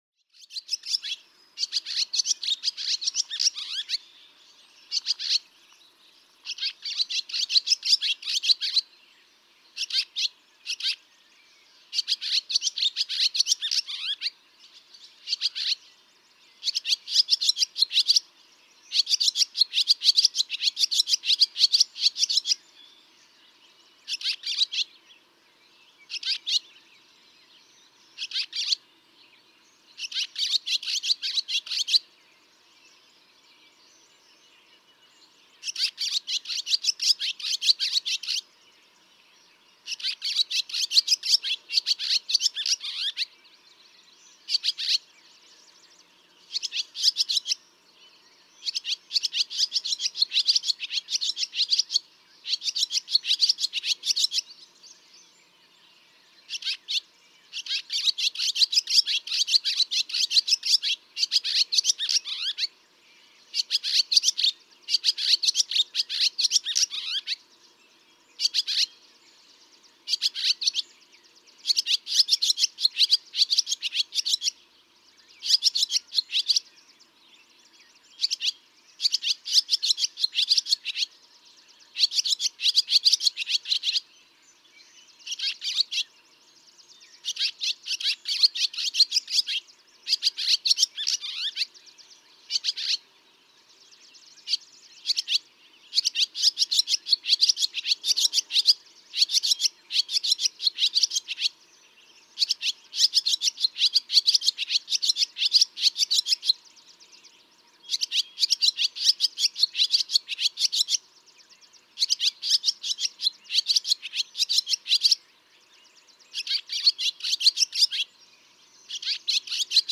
Barn swallow
I hear jumbled, bubbling ecstasy, happiness, in this bird of my childhood.
Whately, Massachusetts.
674_Barn_Swallow.mp3